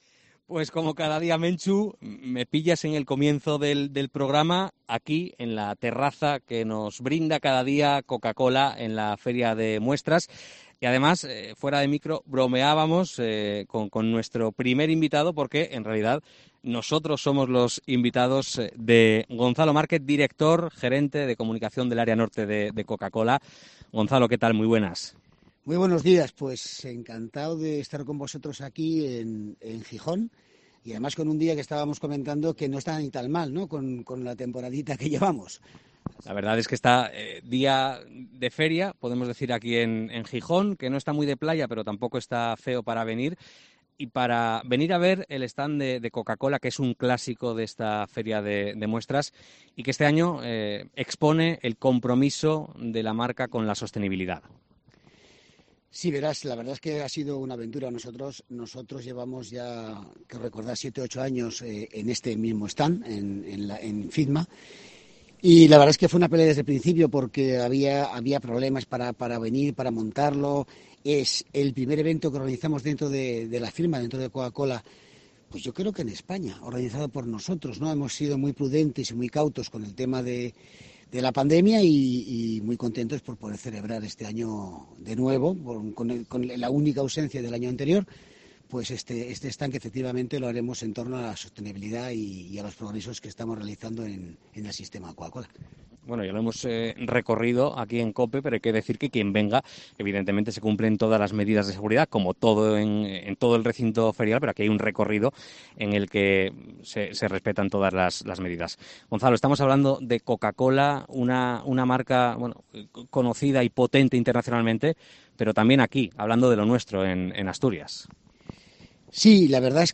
COPE EN LA FIDMA